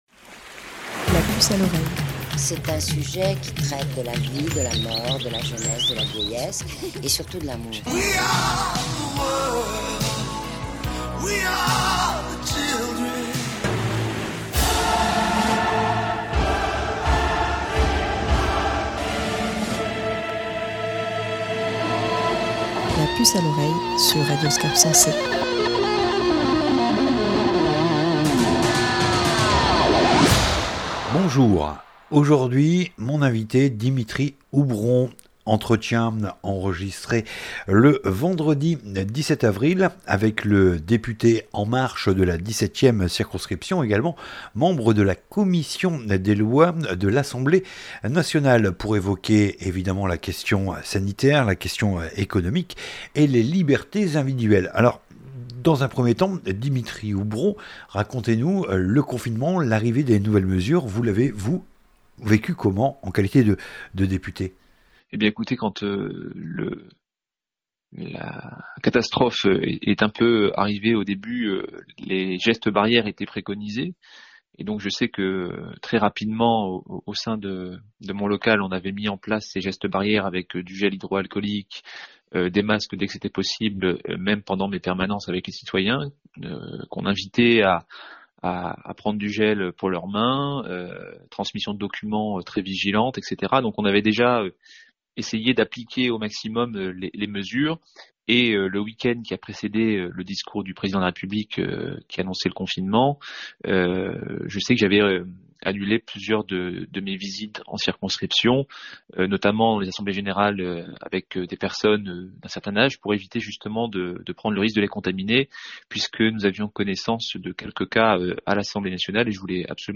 Entretien enregistré le 17 avril pour évoquer les conséquences de la crise sanitaire actuelle et les enseignements à en tirer. Comment protéger sans altérer les libertés individuelles?